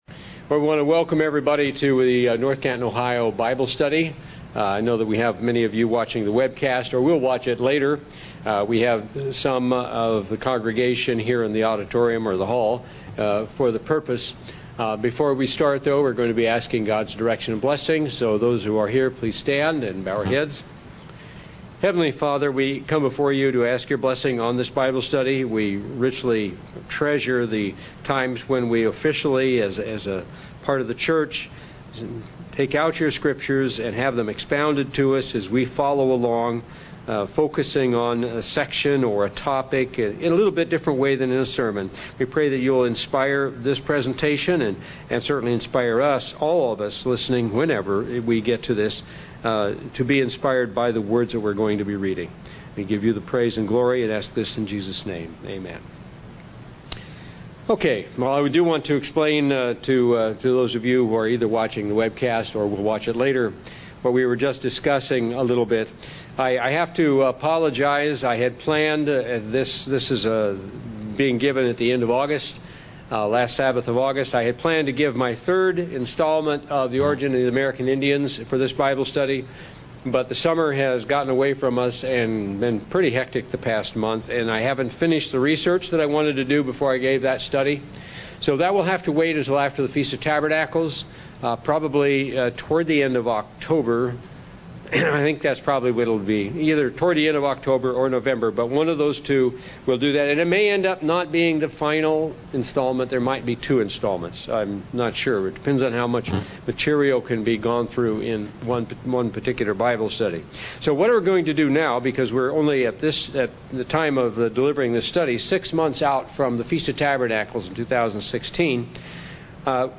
Bible Study: A survey of the Prophecies of the World Tomorrow from Isaiah